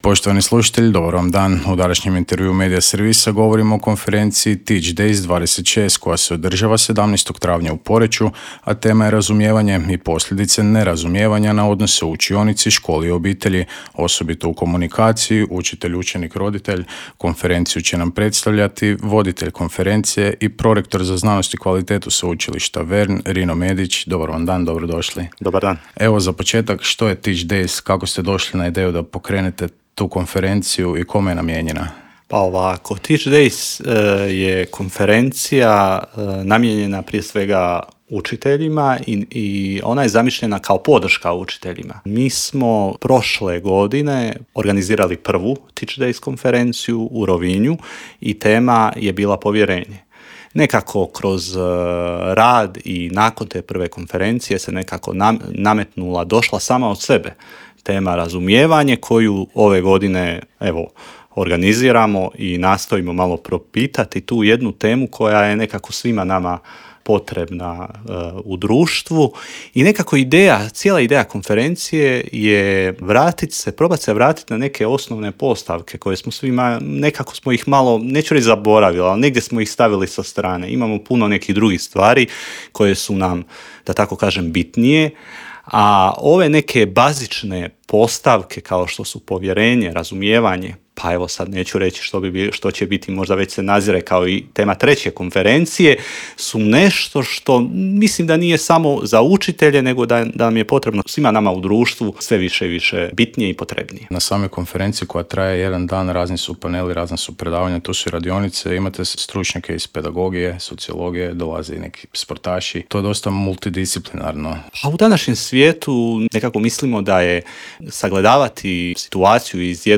ZAGREB - U intervjuu Media servisa govorili smo o konferenciji TeachDays26 koja se održava 17. travnja u Poreču, a tema je razumijevanje i posljedice...